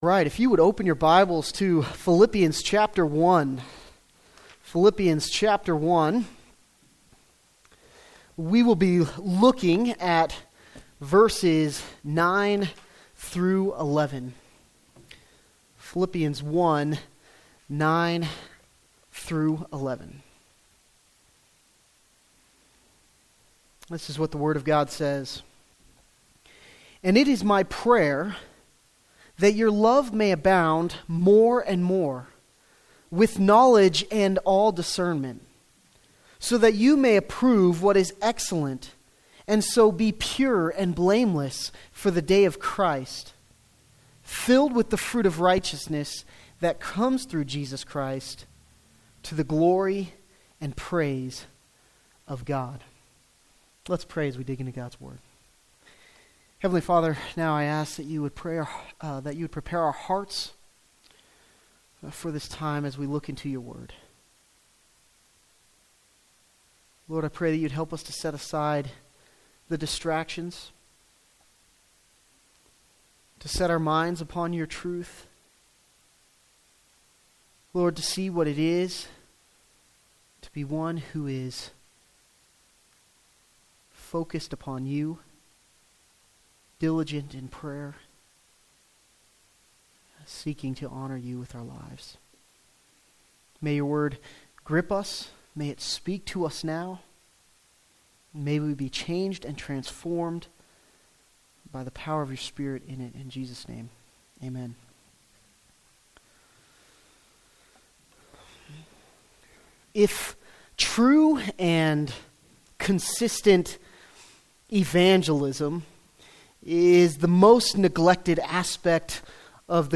*For the Wednesday night service